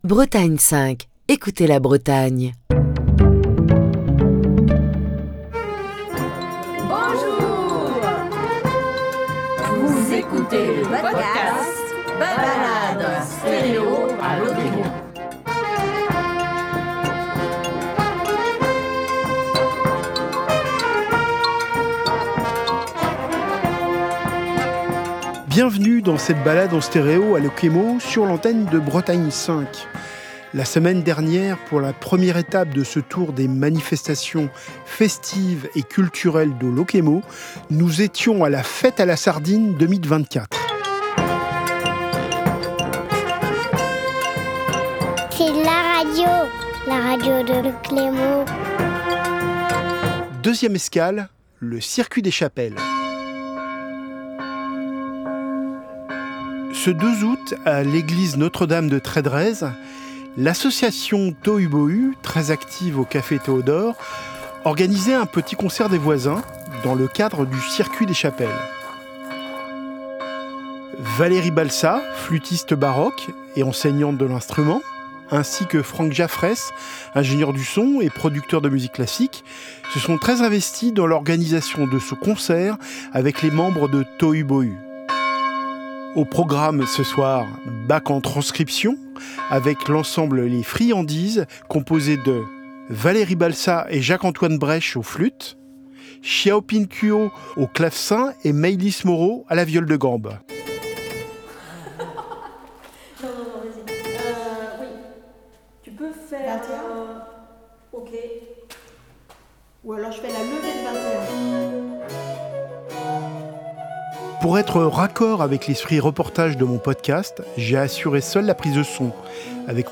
Balade en stéréo à Lokémo - 8ème épisode | Bretagne5
La semaine dernière, pour la première étape de ce tour des manifestations festives et culturelles de Lokémo, nous étions à la Fête à la sardine 2024. Aujourd'hui, nous allons faire un tour dans les concerts du circuit des chapelles.